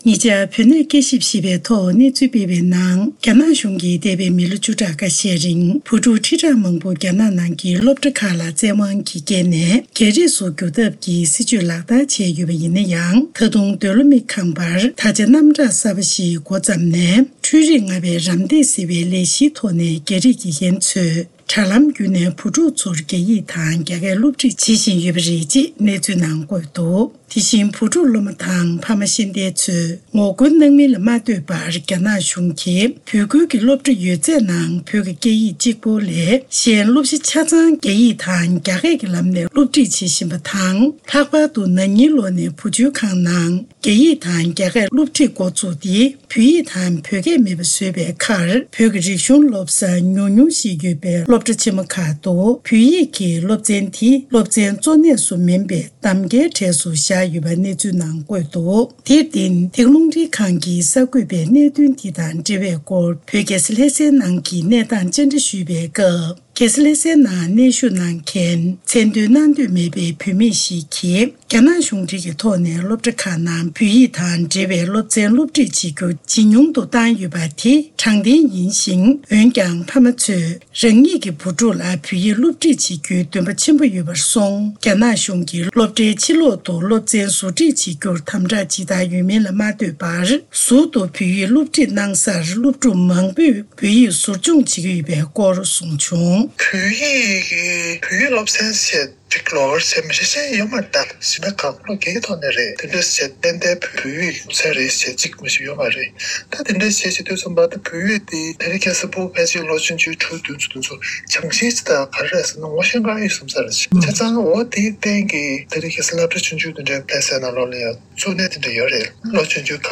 བོད་ཕྱི་ནང་གཉིས་སུ་ཡོད་པའི་འབྲེལ་ཡོད་མི་སྣར་གནས་འདྲི་ཞུས་ཏེ་ཕྱོགས་སྒྲིགས་དང་སྙན་སྒྲོན་ཞུས་པར་གསན་རོགས་ཞུ།།